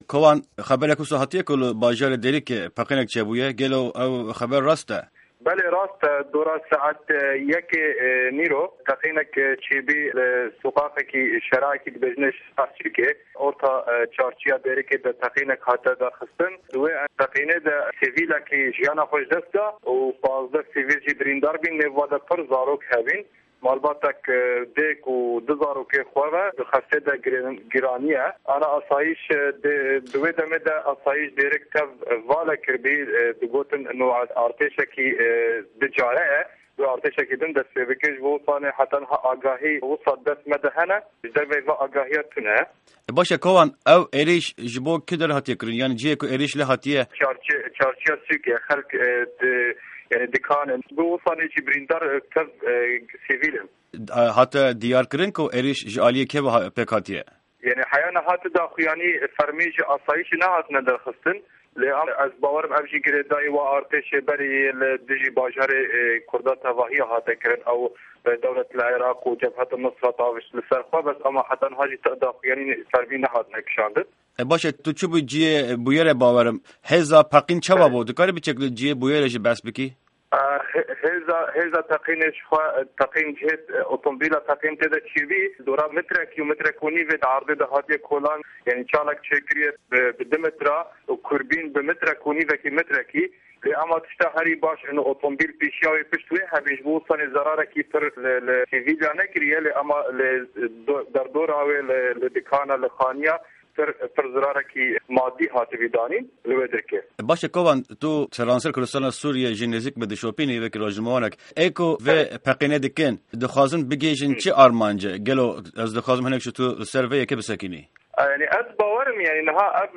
hevpeyvîna rastexwe